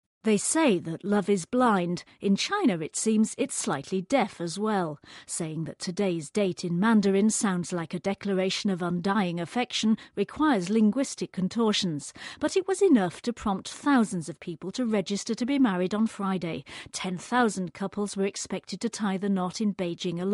【英音模仿秀】数字恋爱 听力文件下载—在线英语听力室